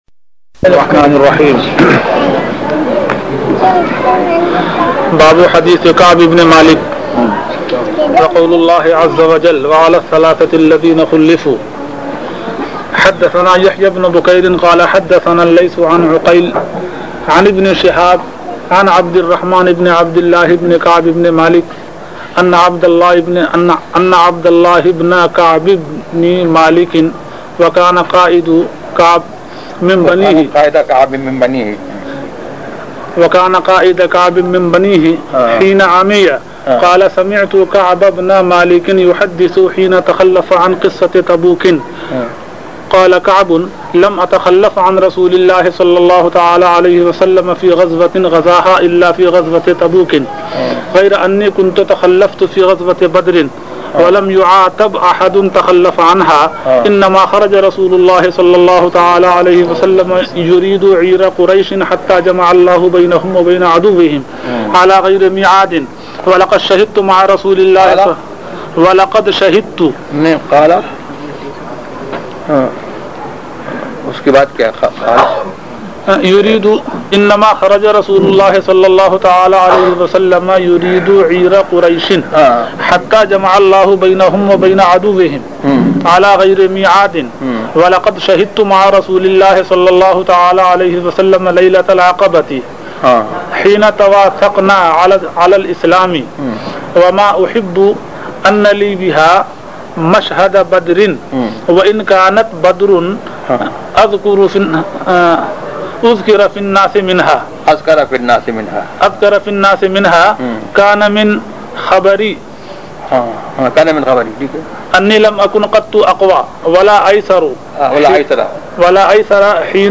درس بخاری شریف ZiaeTaiba Audio میڈیا کی معلومات نام درس بخاری شریف موضوع تقاریر آواز تاج الشریعہ مفتی اختر رضا خان ازہری زبان اُردو کل نتائج 983 قسم آڈیو ڈاؤن لوڈ MP 3 ڈاؤن لوڈ MP 4 متعلقہ تجویزوآراء